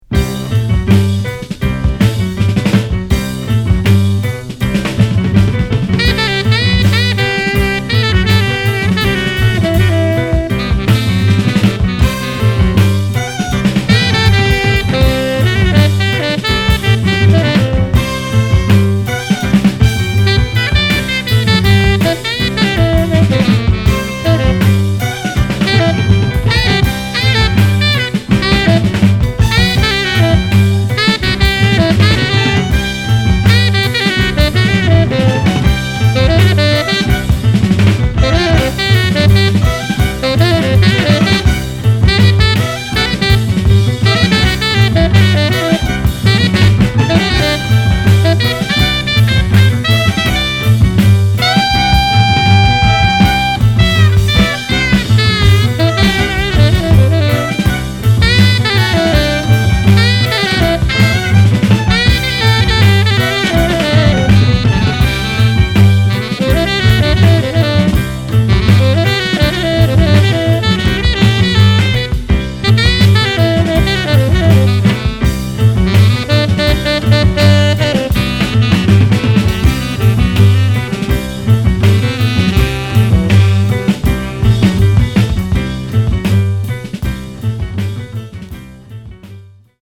磨き上げられたジャズアンサンブルから、フォークロアの息づかいが鮮やかに立ち上がってくる名曲ずらり収録。
キーワード：フォーク　即興　北欧